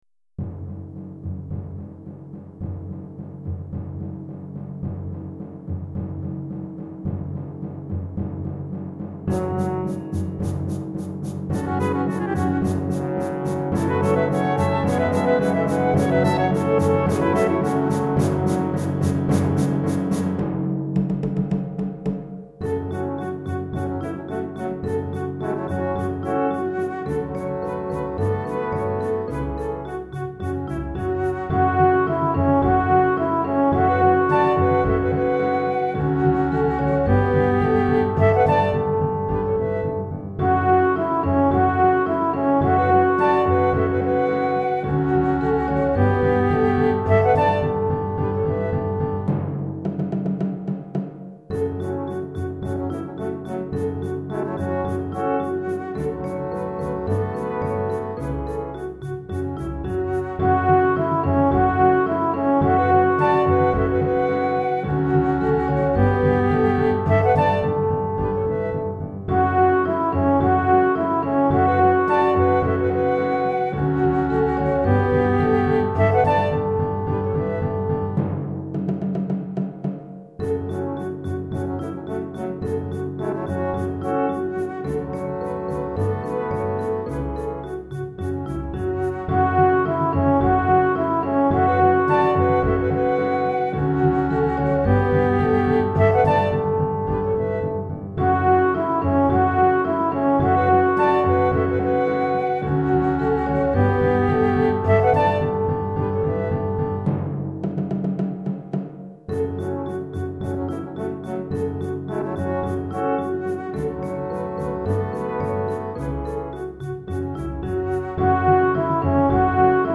Chorale d'Enfants (8 à 11 ans), Flûte Traversière